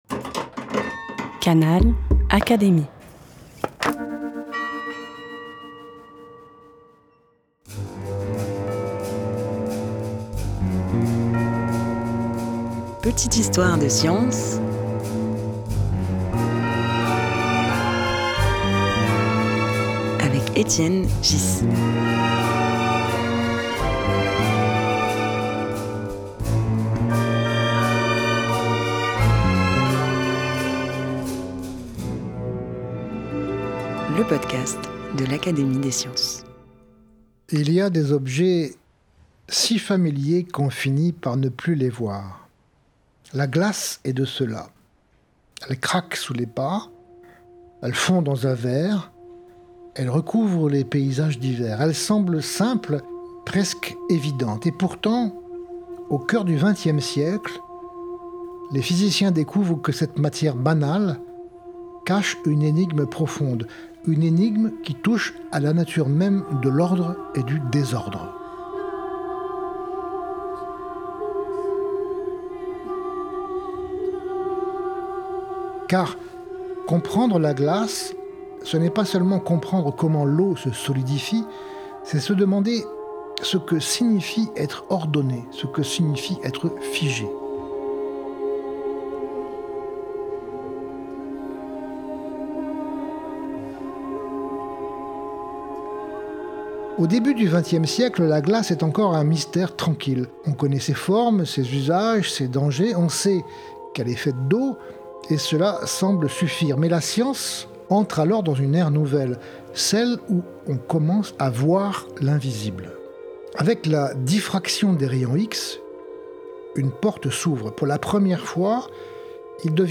Petites histoires de science est un podcast animé par Étienne Ghys, Secrétaire perpétuel de l'Académie des sciences.